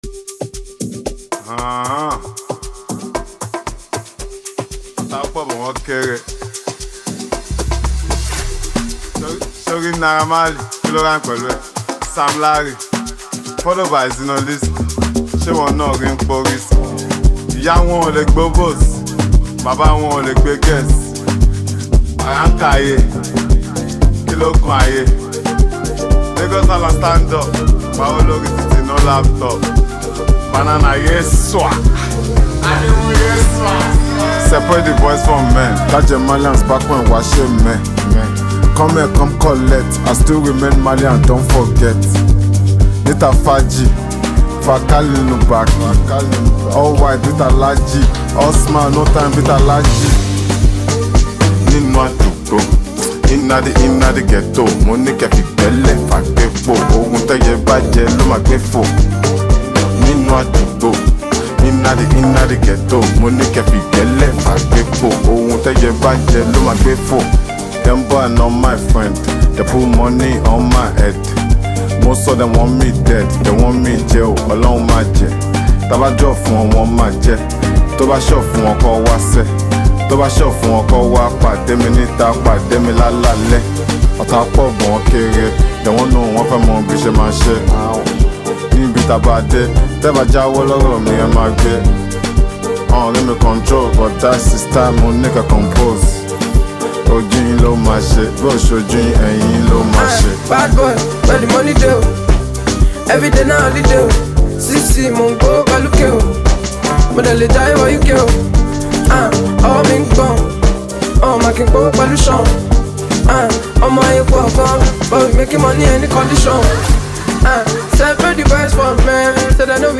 Category Nigerian Music
Genre Afrobeats